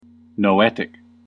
/noʊˈɛt.ɪk(米国英語), nəʊˈɛt.ɪk(英国英語)/